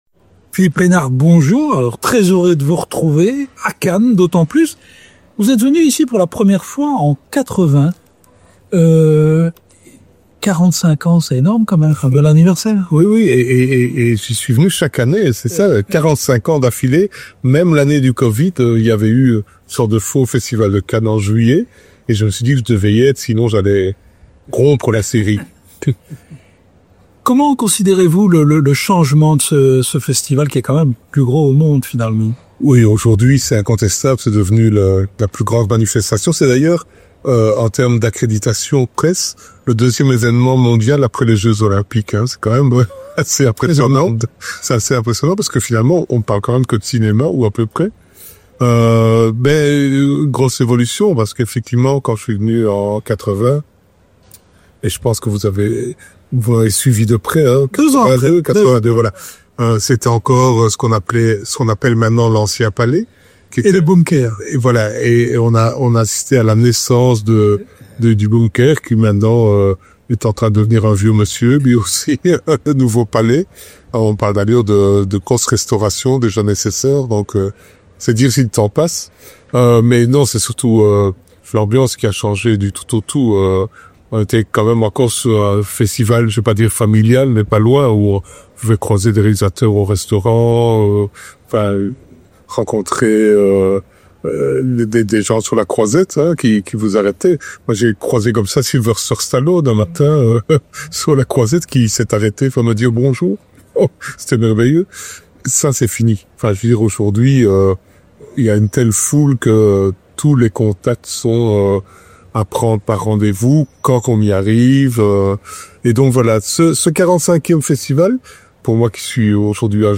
Rencontre.